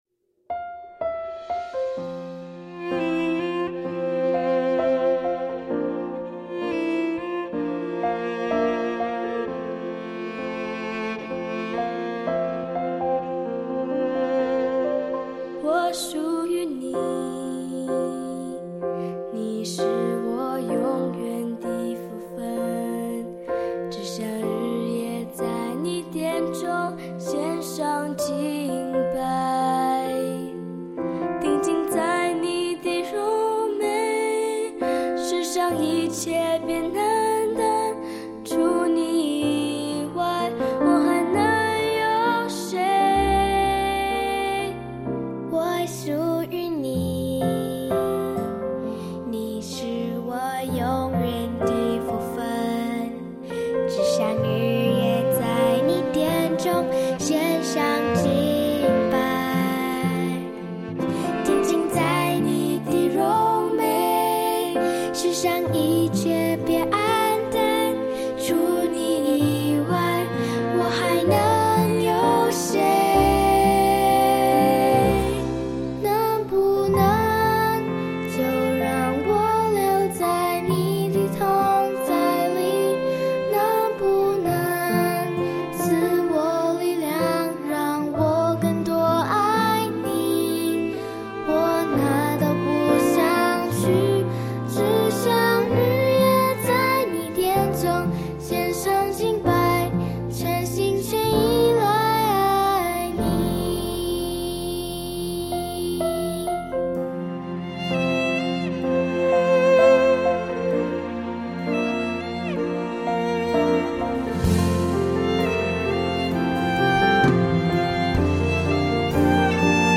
儿童赞美诗 | 能不能